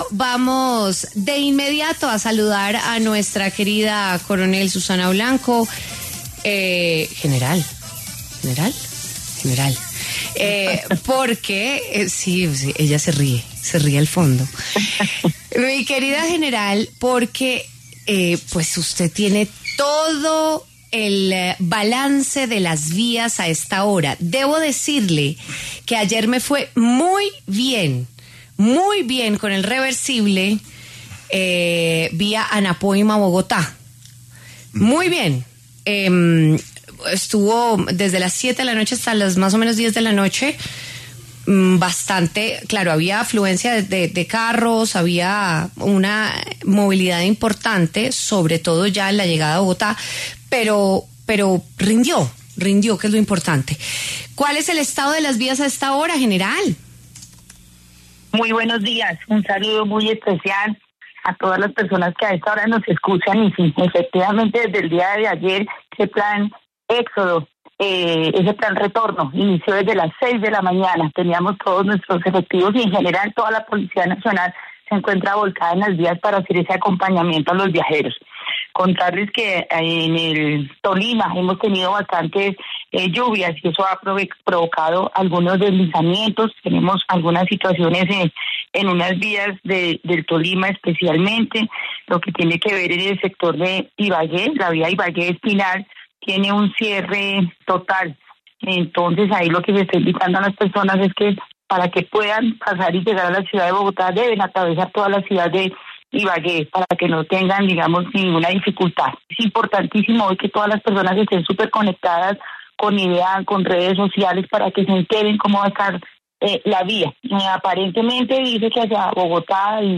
La brigadier general Susana Blanco, directora de la Policía de Tránsito, pasó por los micrófonos de W Fin de Semana para hablar sobre las medidas que están implementando las autoridades en las principales vías del país para el retorno tras la Semana Santa.